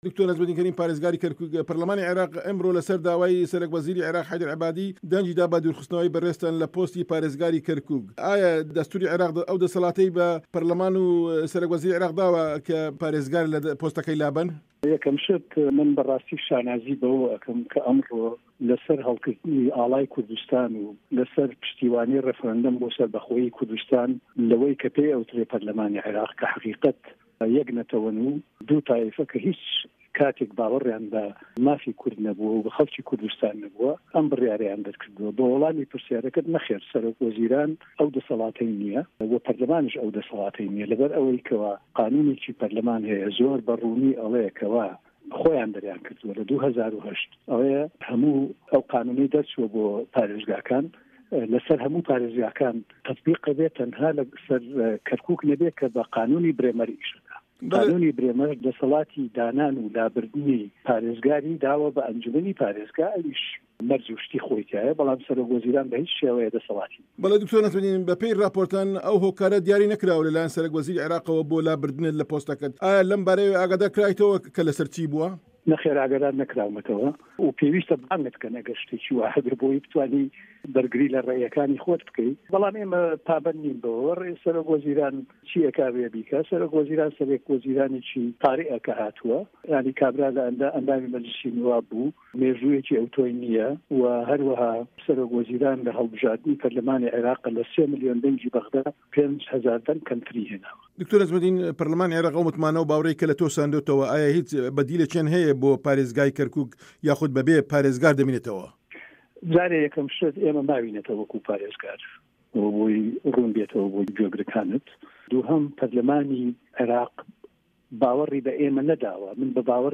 وتووێژ لەگەڵ نەجمەدین کەریم